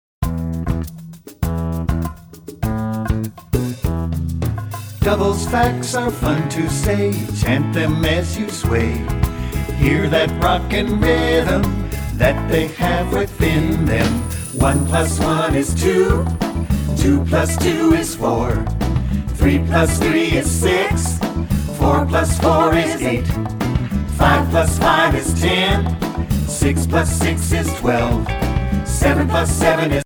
- Mp3 Vocal Song Track